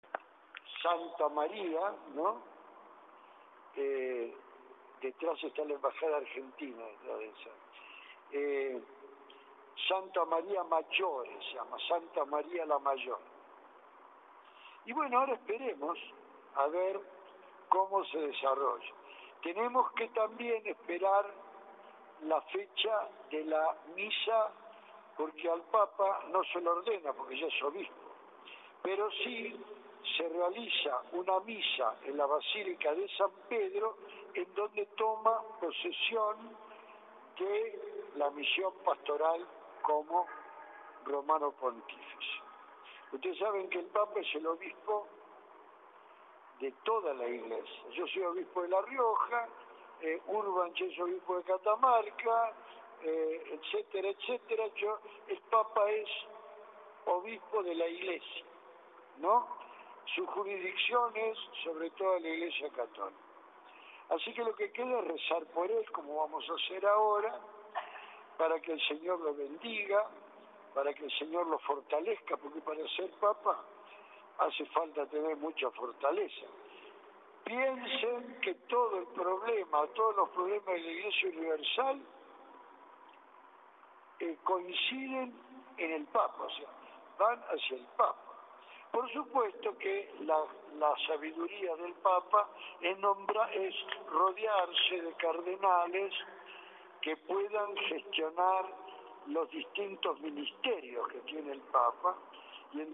El audio del oficio religioso por el jefe de la Iglesia
A través de una improvisada caravana se transmitió un mensaje de fe alrededor de la Plaza 25 de Mayo, para posteriormente el Obispo de La Rioja, Monseñor Roberto Rodríguez, recibiera a los feligreses en la Catedral riojana.
Monseñor Roberto Rodríguez, Obispo de La Rioja
obispo-de-la-rioja.mp3